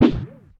hero_jump.ogg